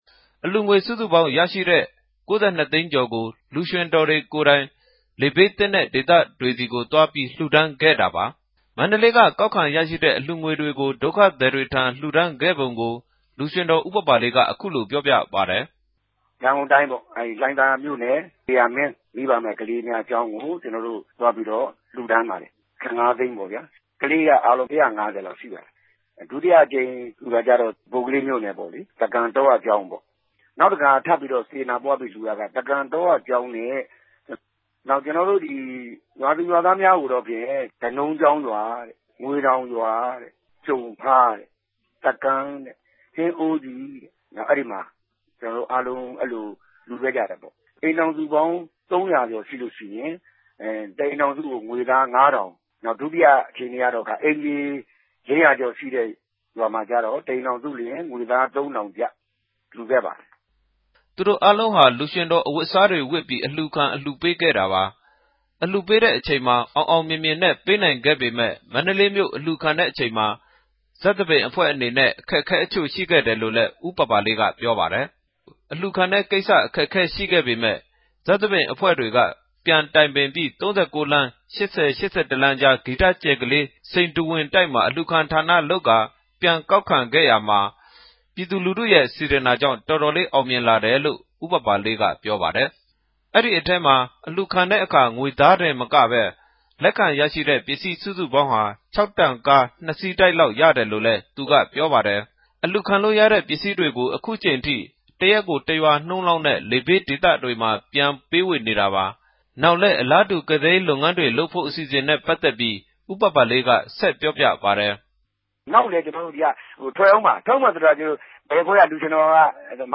သတင်းပေးပိုႛခဵက်။